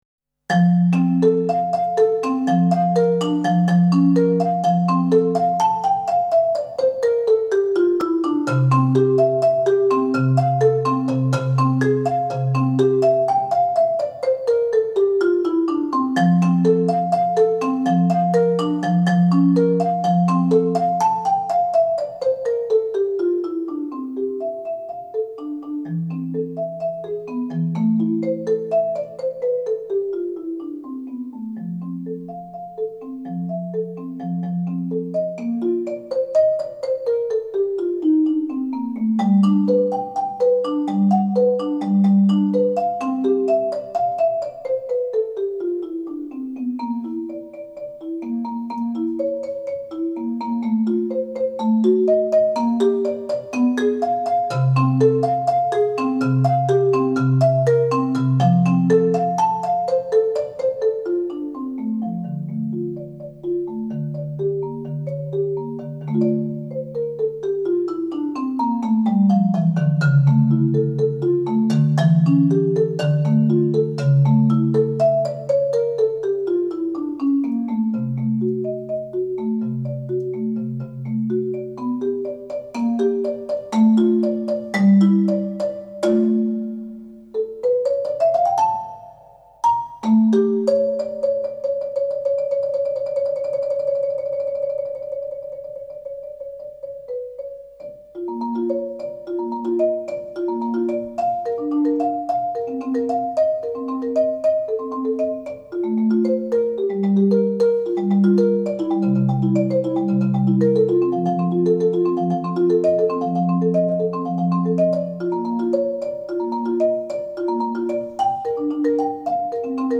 Genre: Marimba (4-mallet)
Marimba (5-octave)